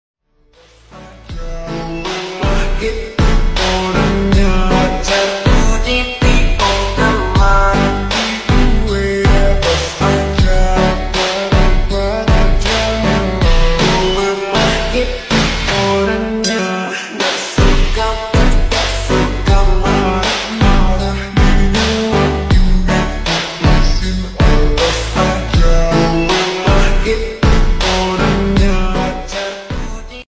edit slowed song